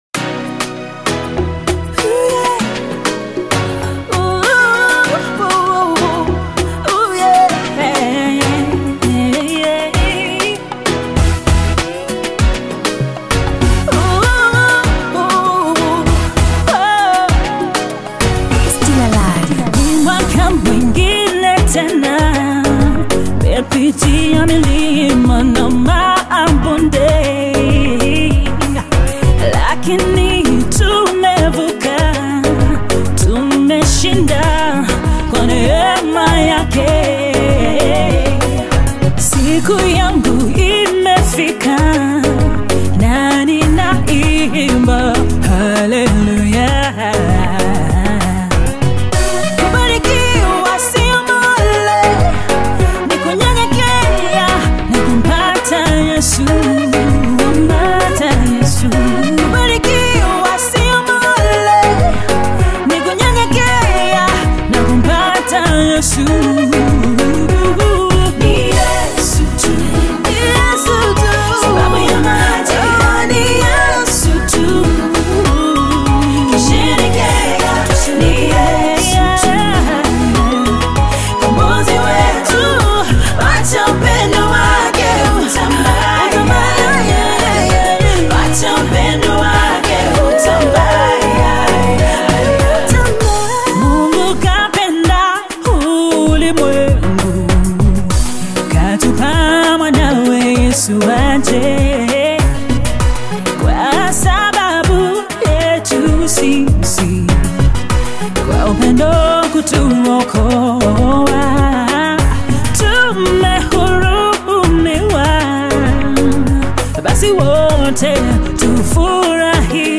GOSPEL AUDIOS